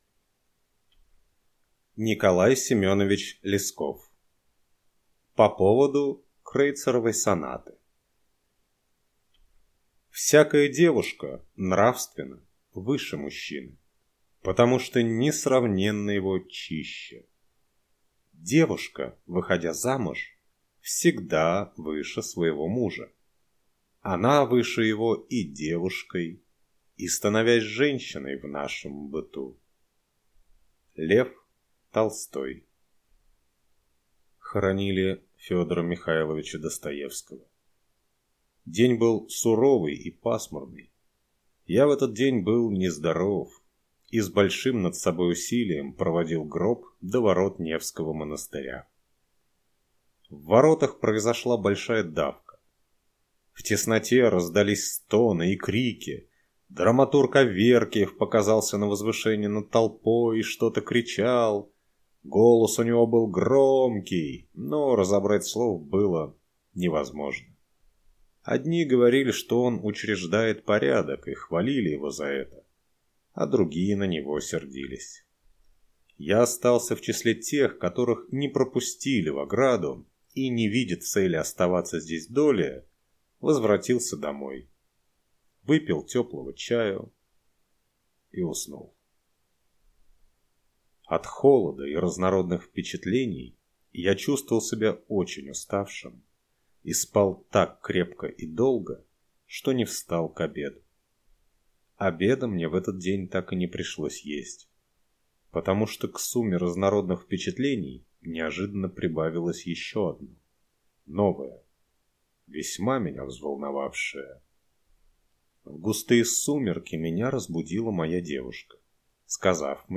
Аудиокнига По поводу «Крейцеровой сонаты» | Библиотека аудиокниг